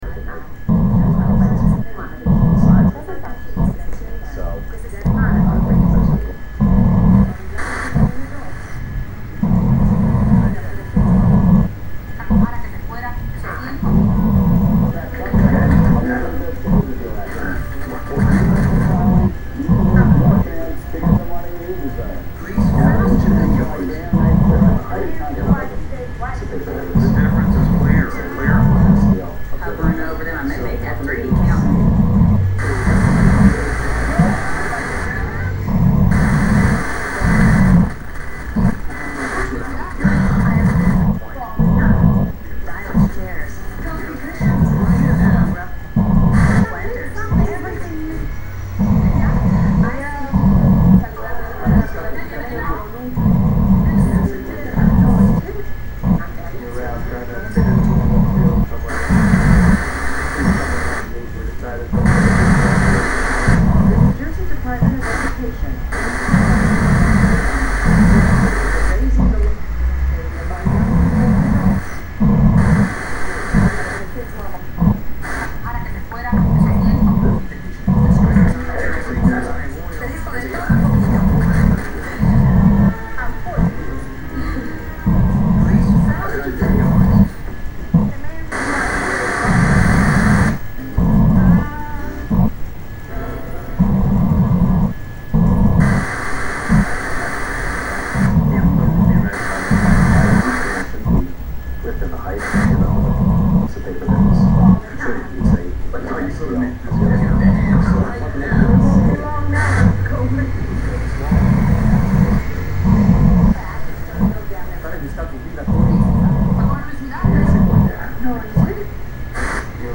This is played with the use of one television one remote and one performer on a couch. The television must be programmed to search or click though stations with signal as well as stations without signal. The stations without signals act like rests in the composition.
The station must be changed at least once every ten seconds the rhythm is based on the performers wishes.
4 different players playing their own tv's